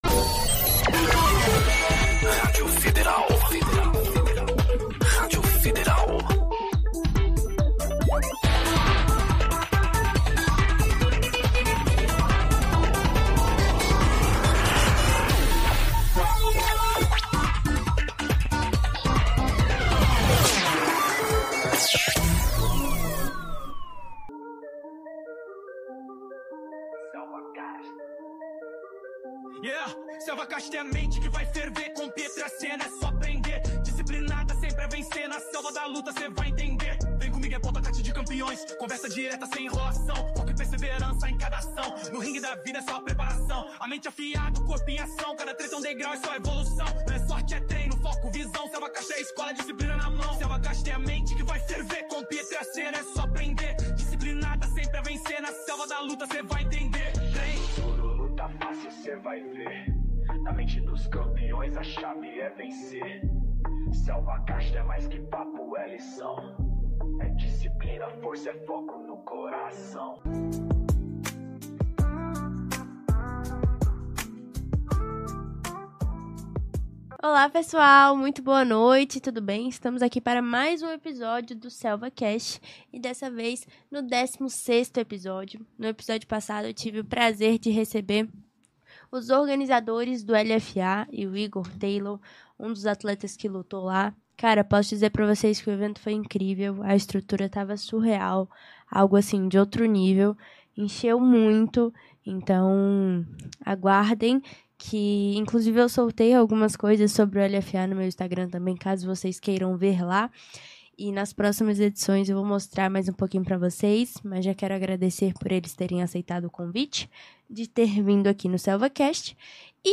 um bate-papo exclusivo